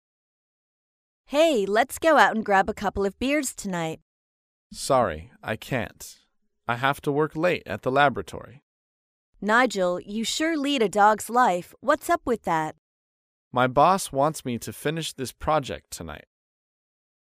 在线英语听力室高频英语口语对话 第65期:婉拒约请(2)的听力文件下载,《高频英语口语对话》栏目包含了日常生活中经常使用的英语情景对话，是学习英语口语，能够帮助英语爱好者在听英语对话的过程中，积累英语口语习语知识，提高英语听说水平，并通过栏目中的中英文字幕和音频MP3文件，提高英语语感。